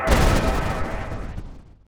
shadowimpact.wav